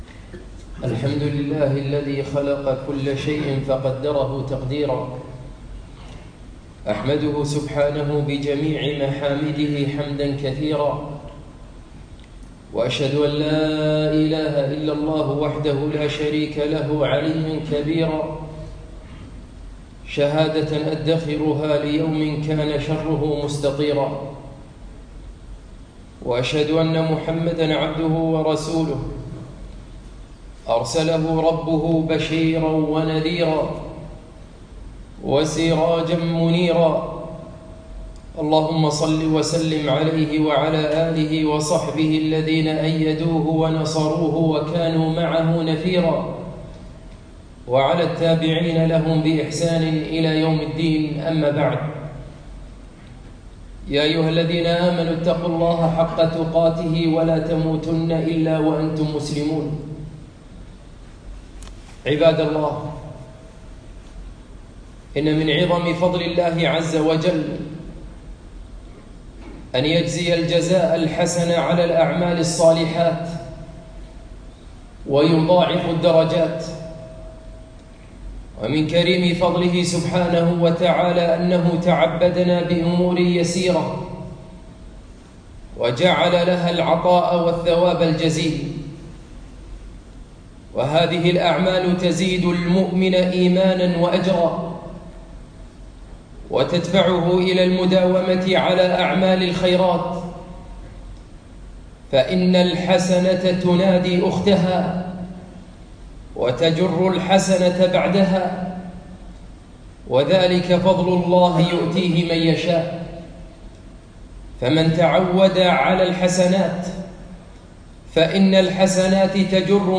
يوم الجمعة 17 ذو الحجة 1438 الموافق 8 9 2017 في مسجد العلابن عقبة الفردوس